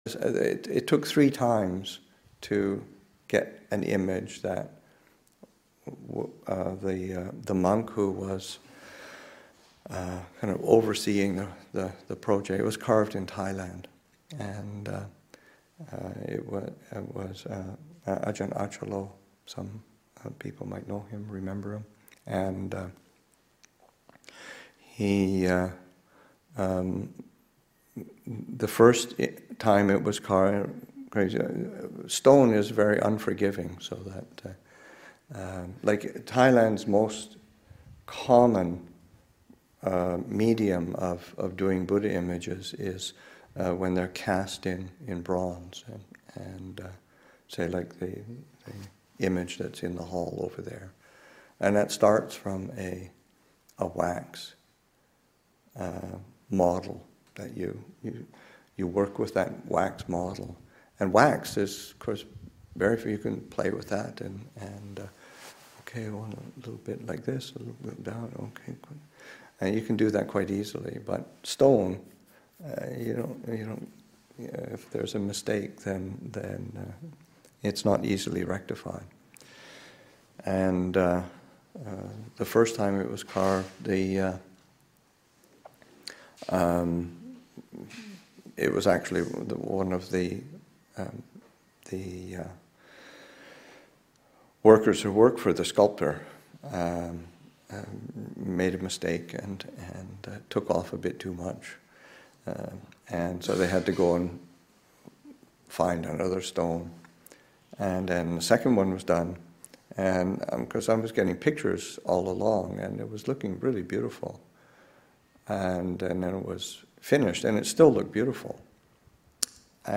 Story: It took three tries to make Abhayagiri’s sandstone Buddha image.